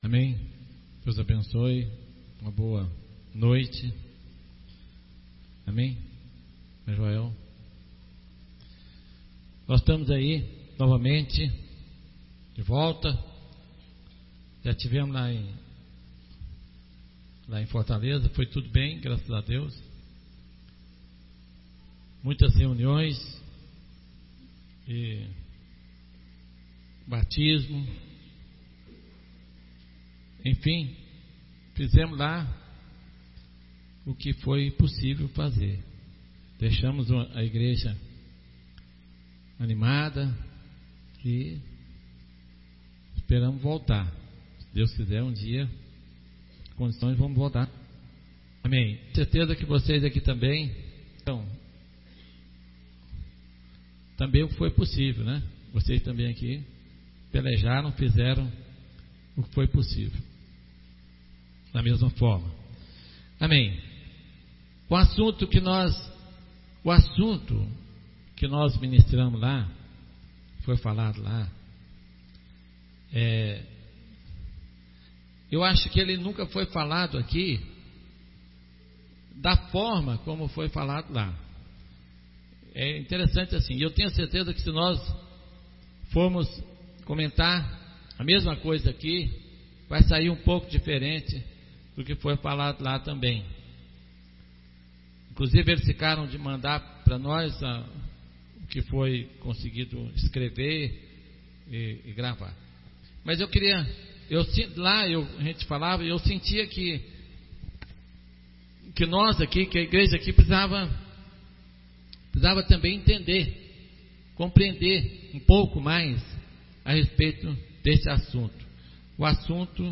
Reunião 2005-04-27 – Como o pecado entrou no mundo – quarta-feira | Povo de Deus